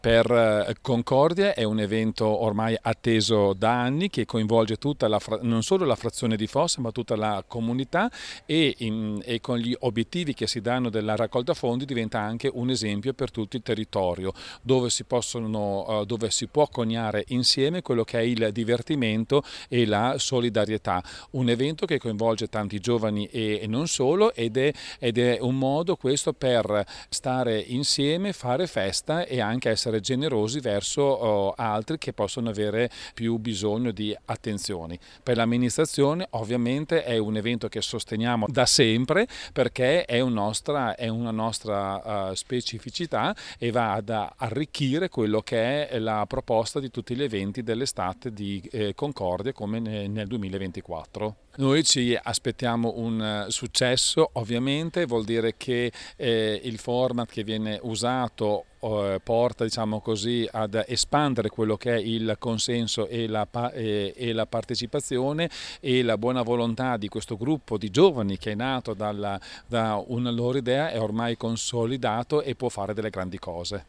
nella giornata di presentazione
Prandini Luca, Sindaco di Concordia